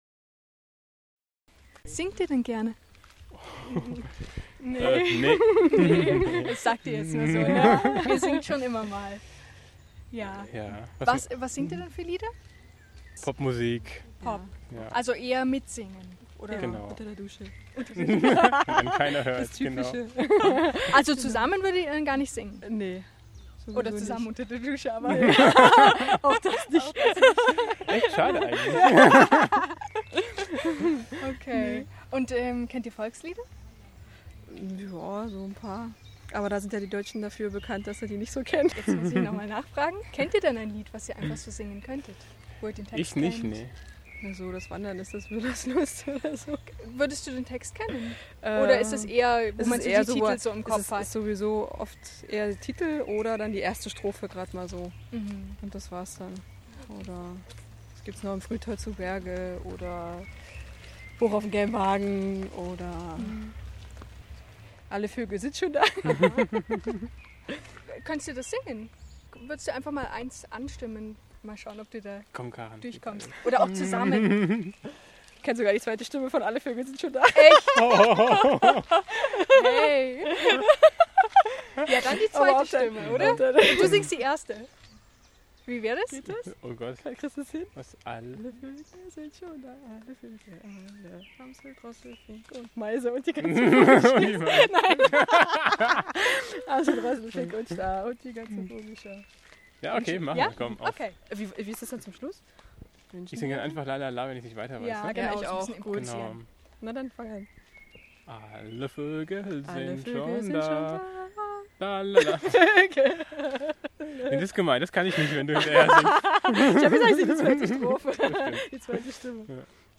she recorded hundreds of people singing a song of their choice and offered a drawing as a currency.